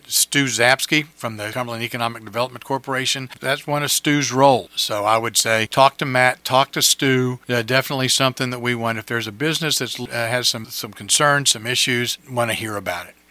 A recent caller to WCBC asked if established businesses have access to the same kind of help in getting businesses to grow.  Mayor Ray Morriss said the answer is yes – and pointed to the Cumberland Economic Development Corporation…